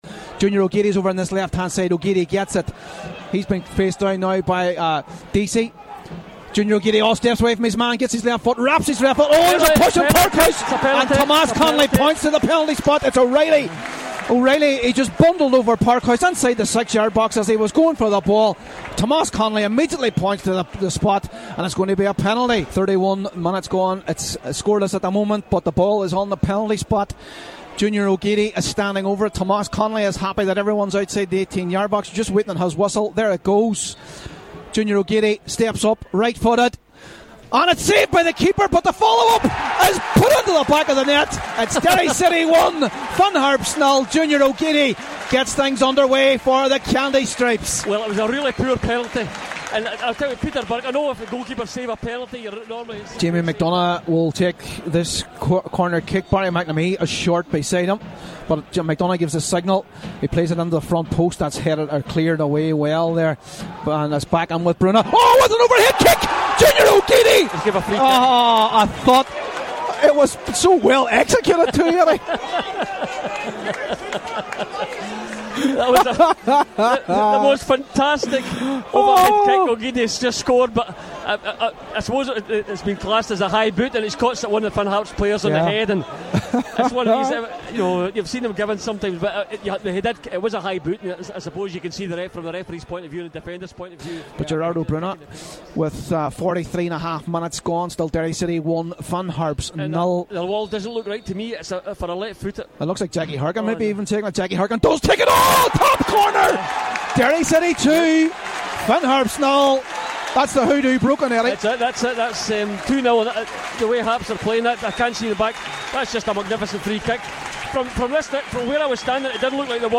Derry City 4-0 Finn Harps - Goal Highlights